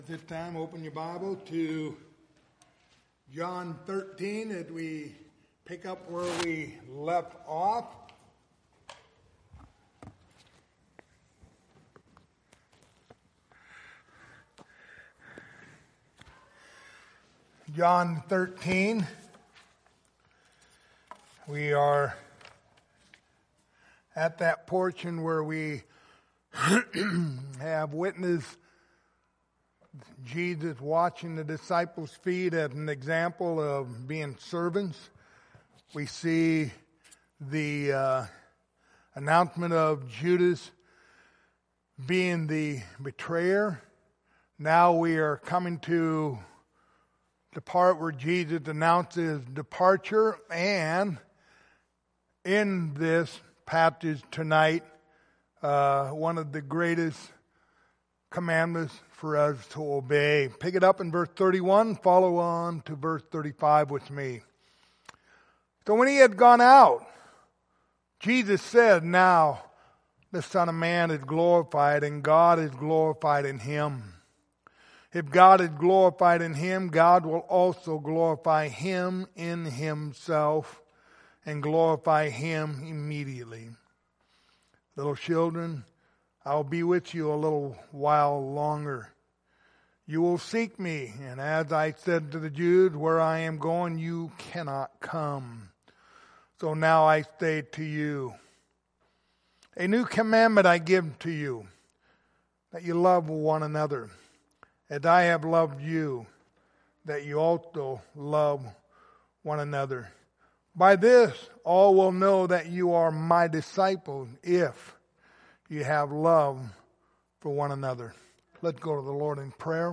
The Gospel of John Passage: John 13:31-35 Service Type: Wednesday Evening Topics